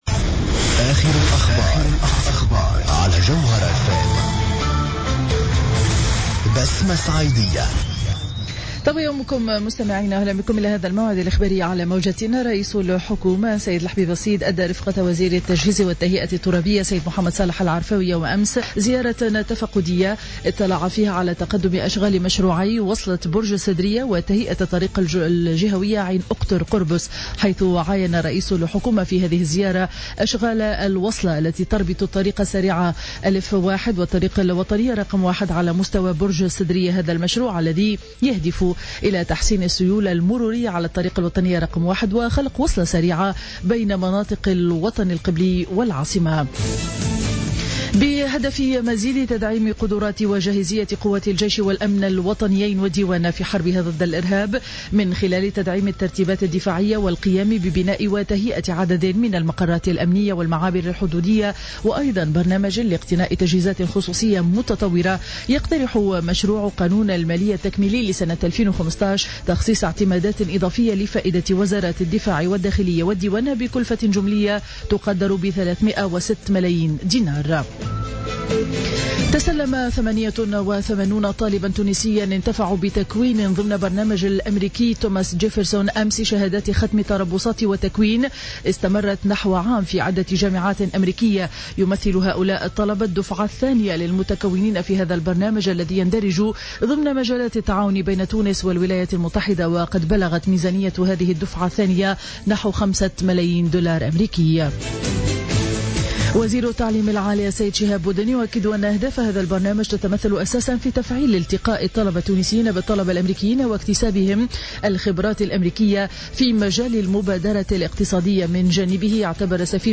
نشرة أخبار السابعة صباحا ليوم الإثنين 27 جويلية 2015